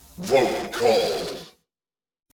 🌲 / midnight_guns mguns mgpak0.pk3dir sound announcer
vote_called_00.wav